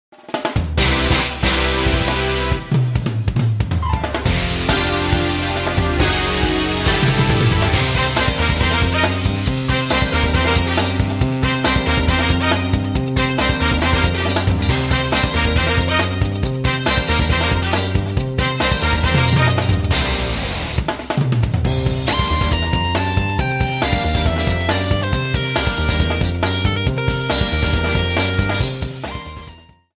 arrangement